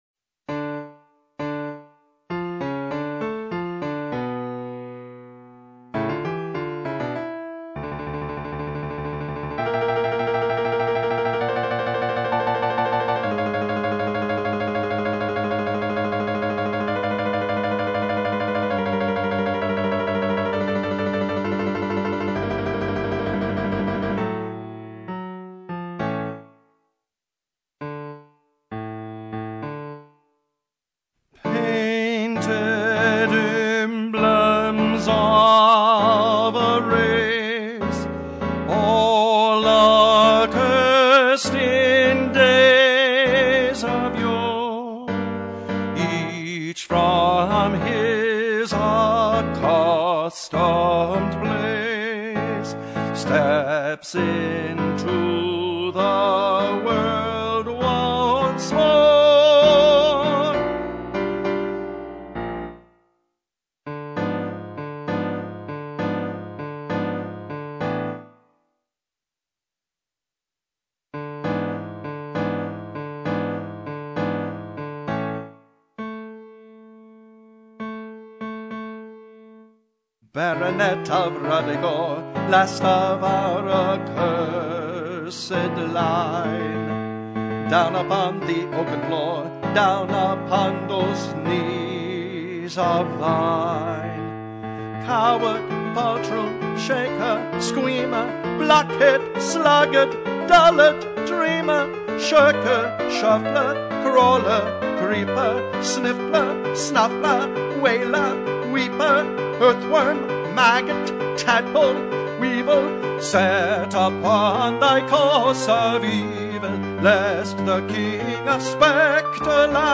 G&S Chorus Practice - Ruddigore
Quality and intonation are sometimes marginal.
As a bonus, the featured choral parts are sung, not synthesized as in recent demos.